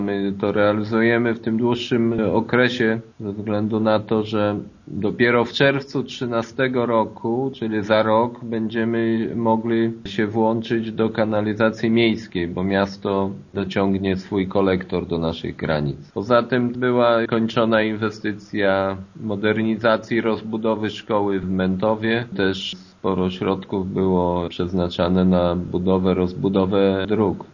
Wszystkie ważne zadania, które były planowane zostały zrealizowane, a najwięcej pieniędzy kosztuje rozłożona na kilka lat budowa kanalizacji sanitarnej – mówi Jacek Anasiewicz: